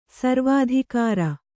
♪ sarvādhikāra